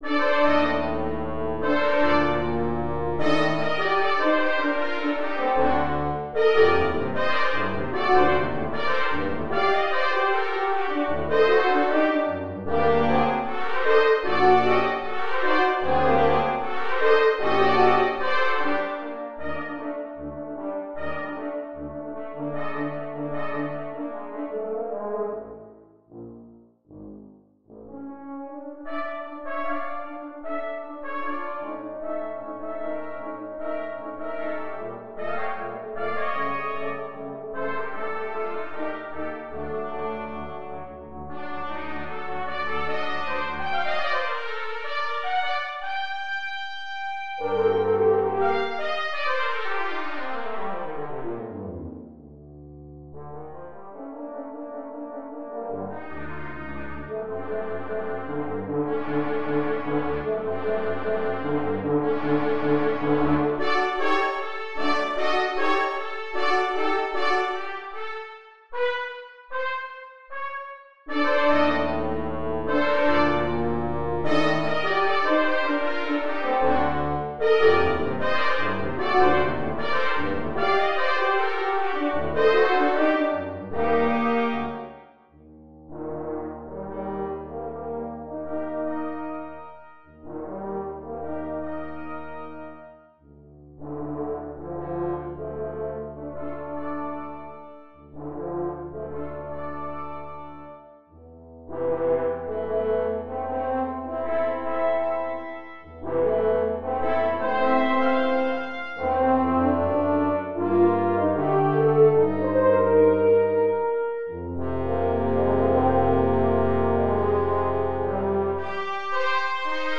Mercia Brass Quintet No. 3 The King’s Messenger (MIDI)